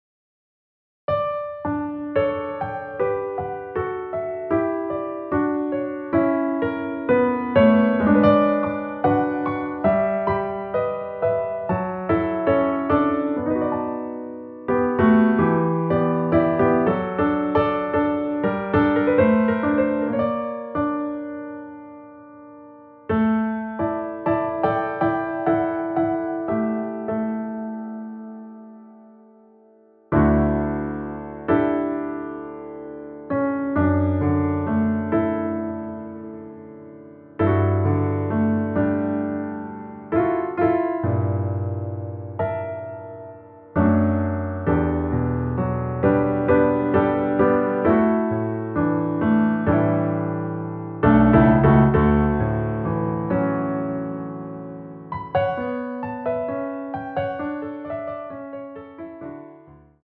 원키에서(-3)내린 MR입니다.
◈ 곡명 옆 (-1)은 반음 내림, (+1)은 반음 올림 입니다.
앞부분30초, 뒷부분30초씩 편집해서 올려 드리고 있습니다.
중간에 음이 끈어지고 다시 나오는 이유는